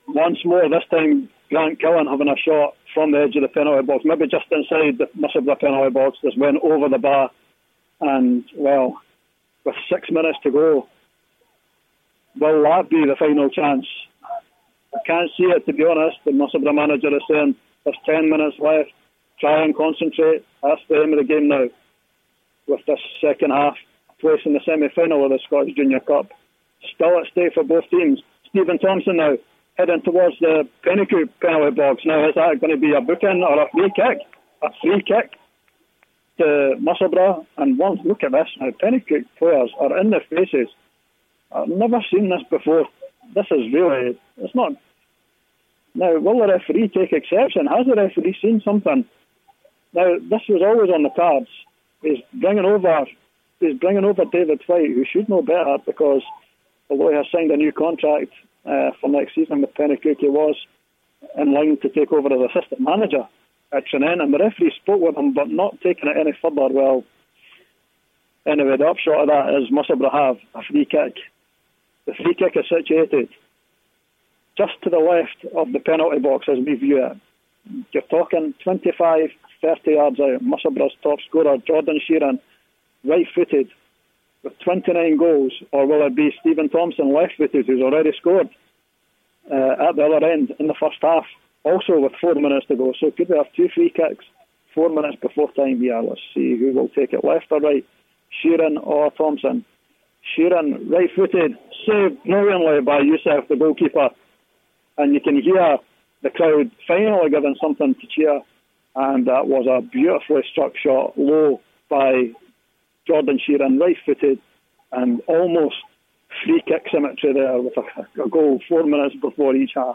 The bonus for the Musselburgh supporters and team, the second goal live on Black Diamond FM